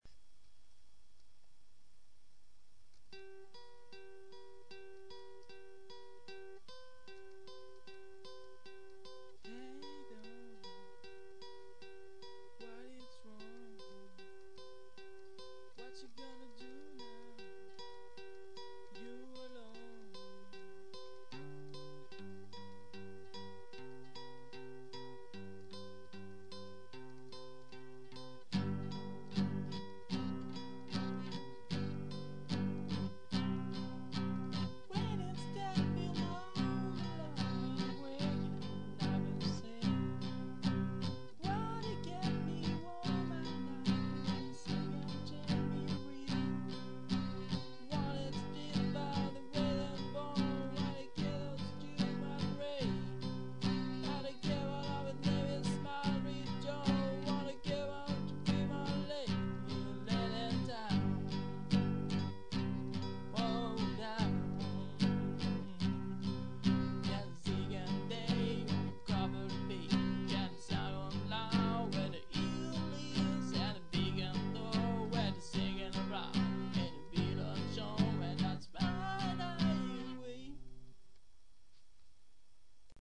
15. Don Edge (Acoustic)